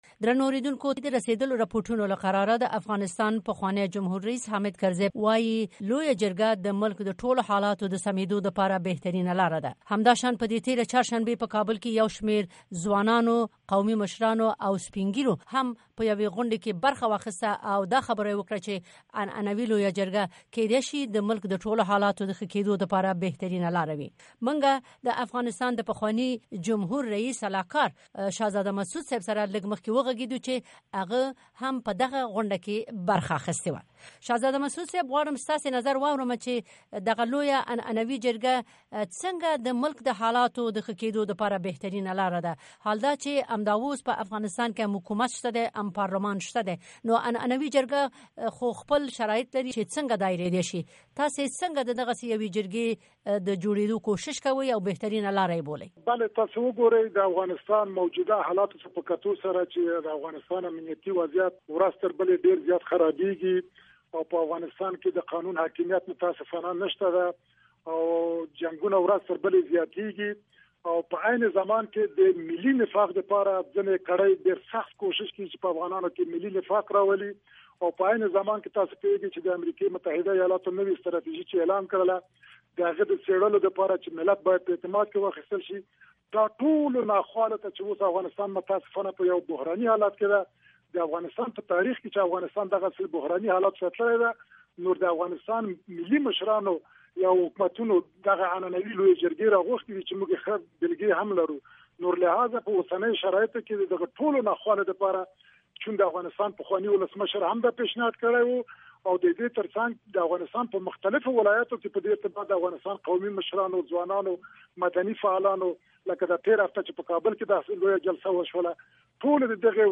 د شهزاده مسعود سره مرکه